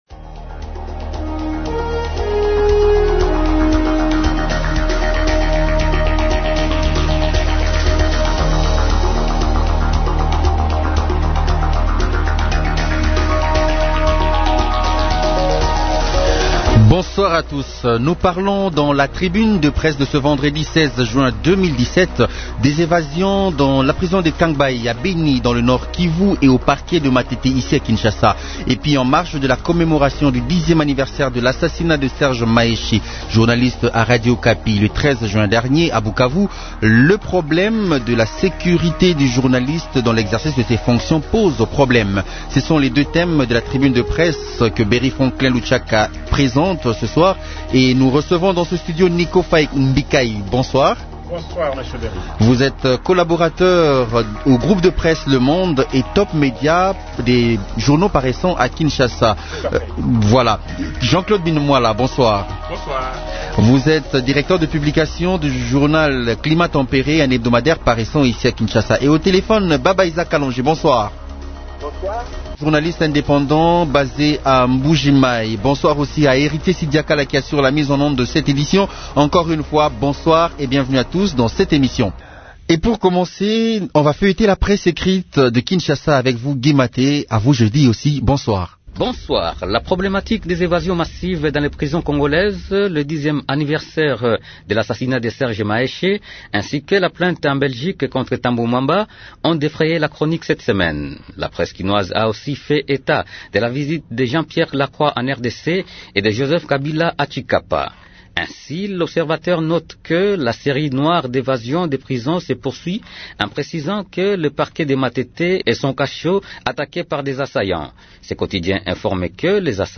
Les journalistes invités dans "Tribune de la presse" commentent notamment les récentes évasions enregistrées dans le pays.